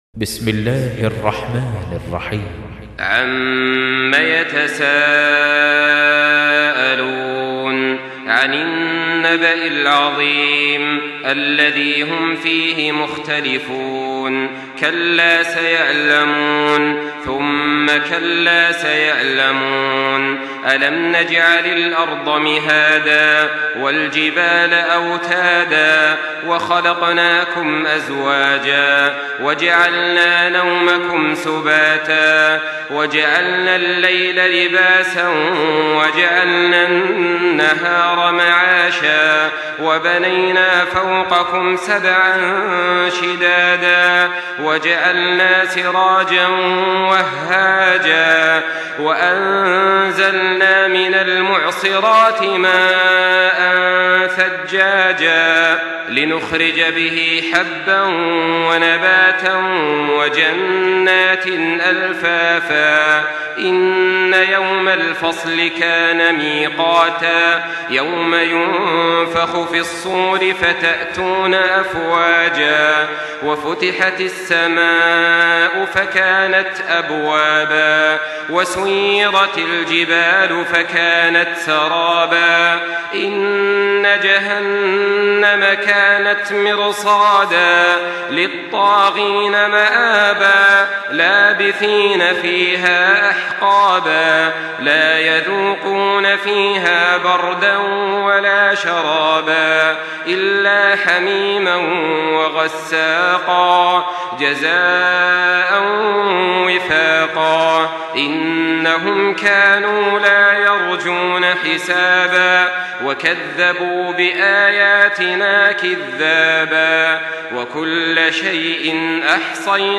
Surah النبأ MP3 by تراويح الحرم المكي 1424 in حفص عن عاصم narration.
مرتل